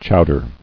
[chow·der]